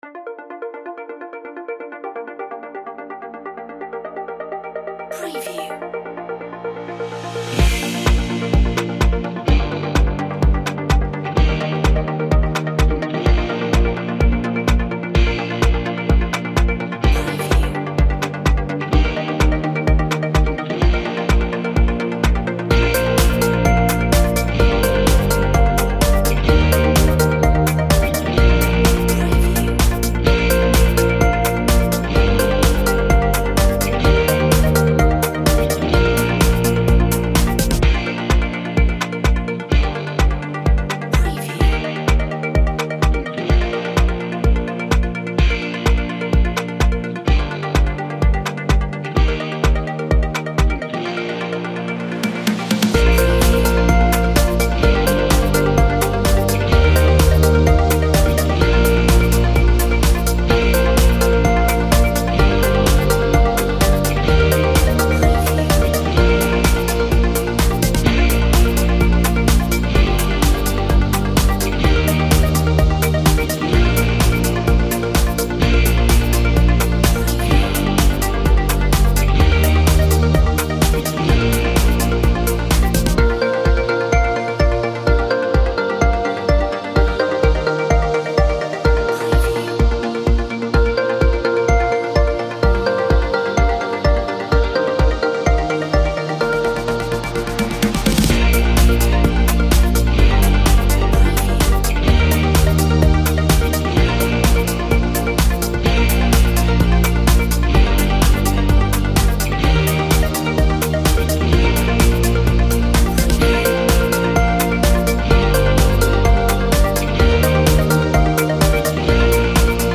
Upbeat inspiration